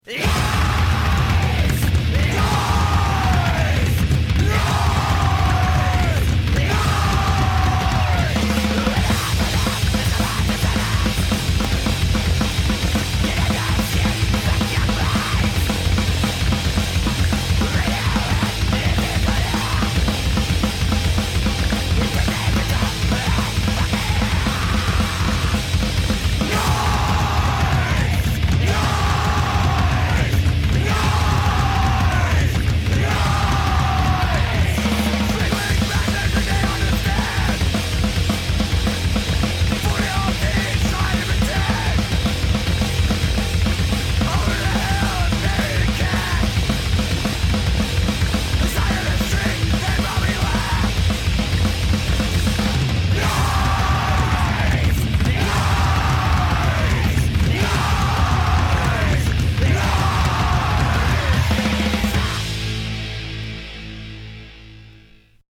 guitar, vocals, bass
guitar, vocals, drums